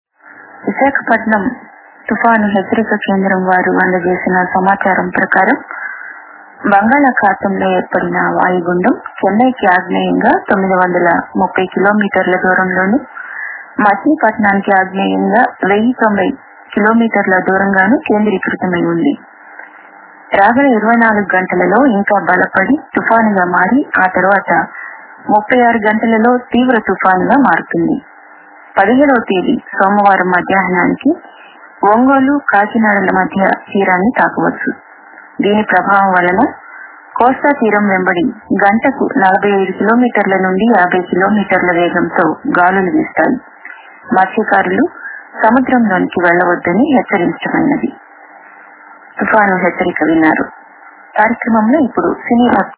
Cyclone-Alert-AIR-News.mp3